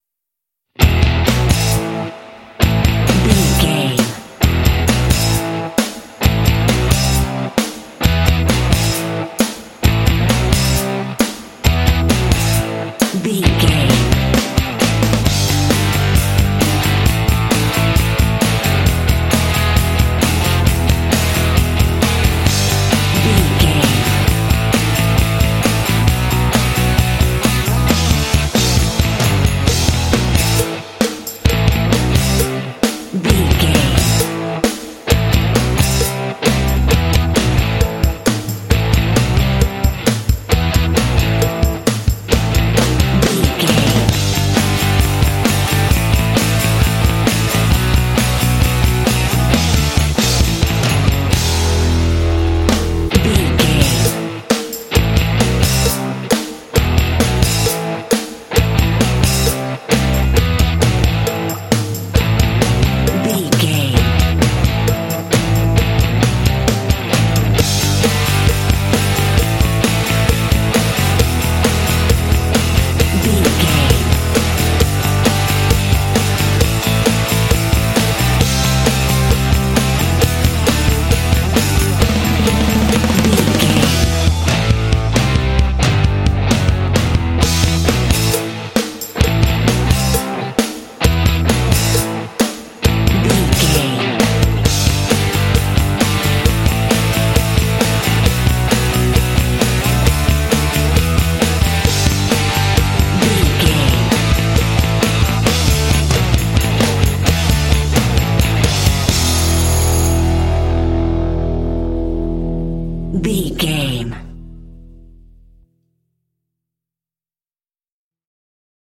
Aeolian/Minor
powerful
energetic
heavy
electric guitar
drums
bass guitar
percussion
rock
heavy metal
classic rock